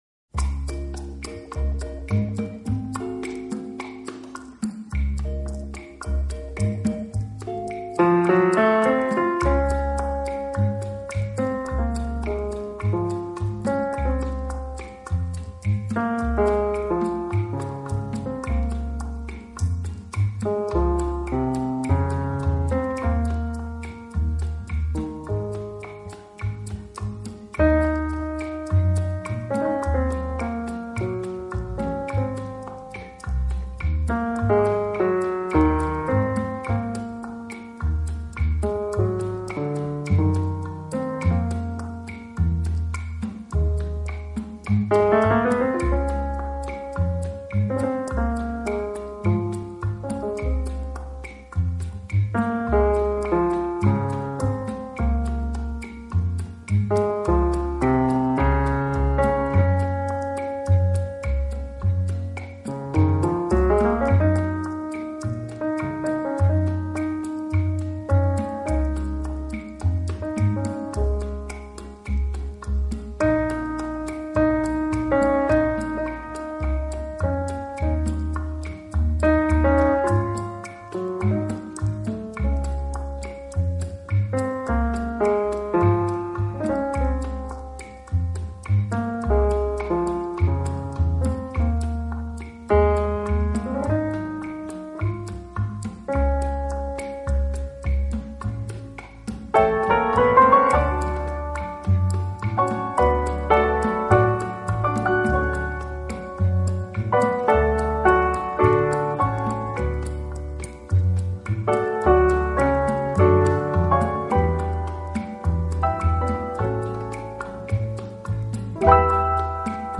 【轻音乐专辑】
演奏以轻音乐和舞曲为主。